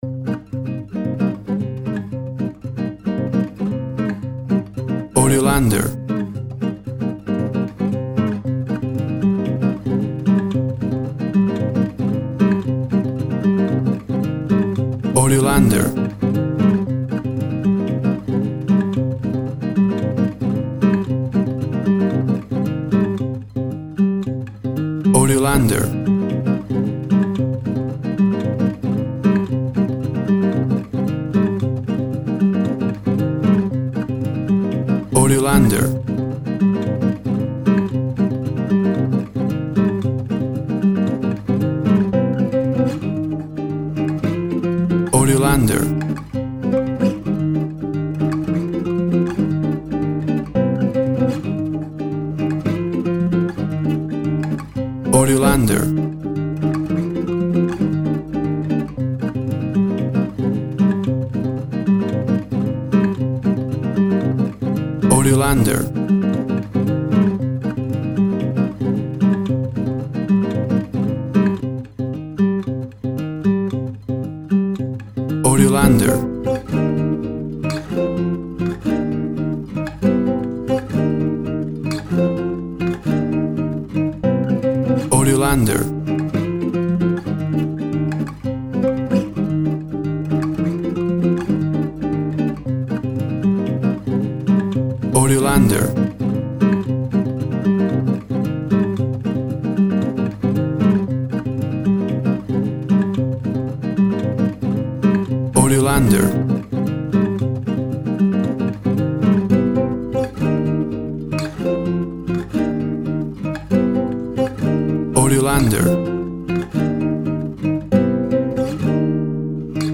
Tempo (BPM) 120